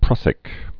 (prŭsĭk)